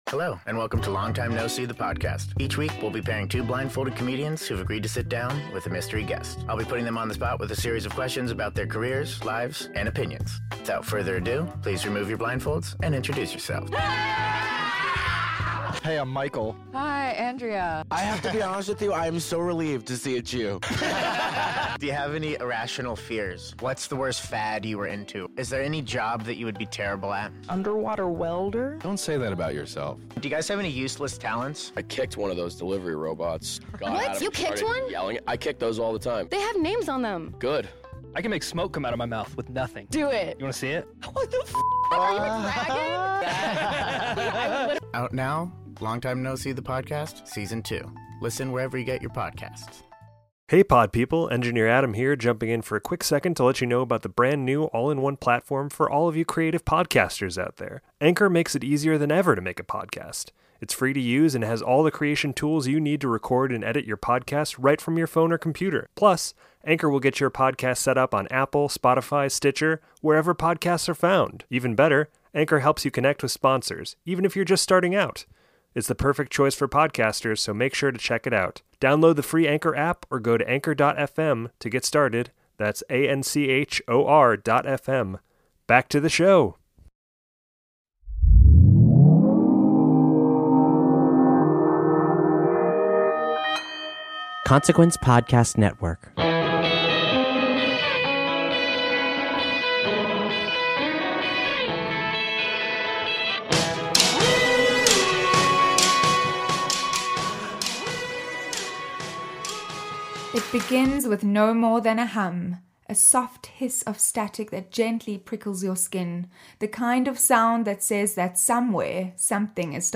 This Must Be the Gig gives backstage access to passionate fans worldwide. Featuring conversations with headlining musicians, up-and-comers, and music industry personalities about the wild wild world of live music - as well as the latest news reports from the biggest tours and the festival scene.
Genres: Music, Music Interviews
Trailer: